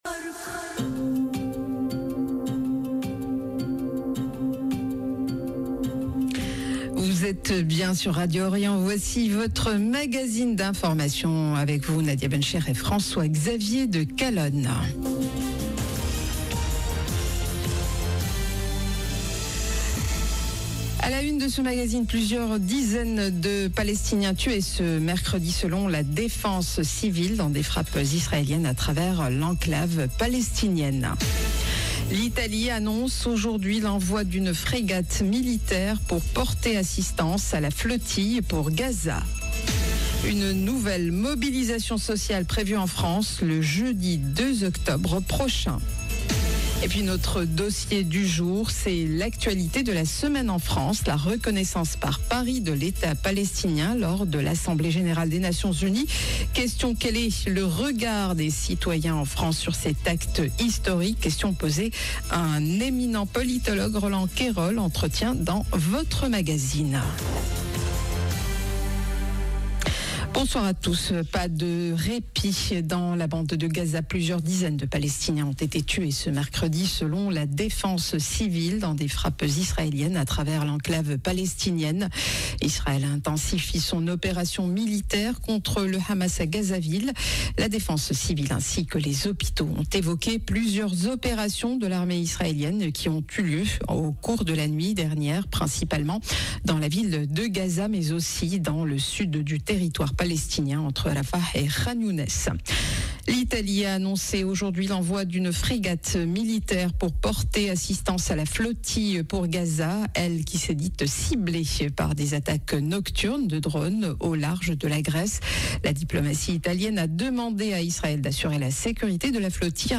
Journal de 17H du 24 septembre 2025